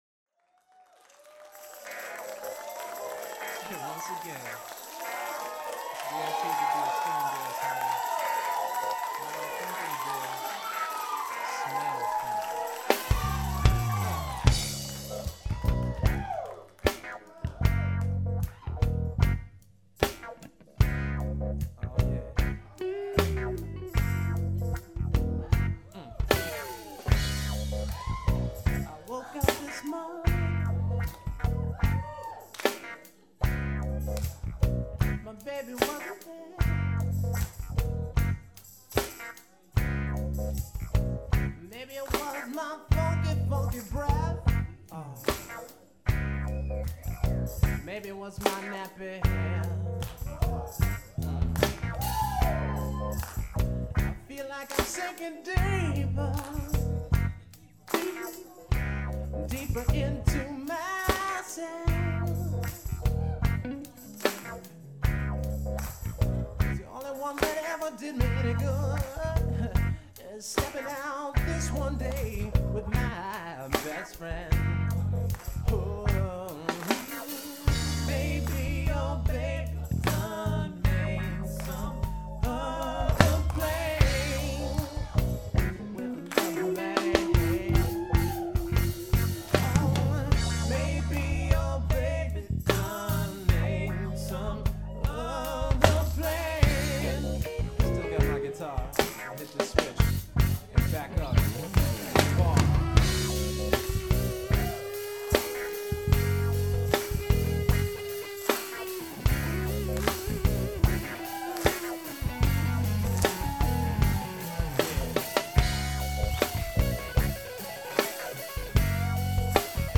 Guess where we recorded this "live" nugget?